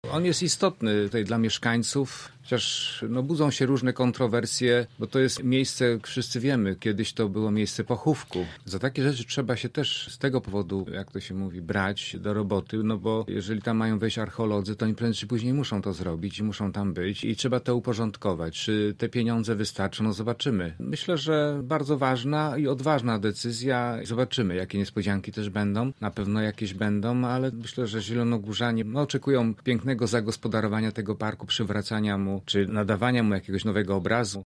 Radny z klubu Prawa i Sprawiedliwości przypomina, że na tym terenie był cmentarz:
Marek Budniak był gościem Rozmowy po 9.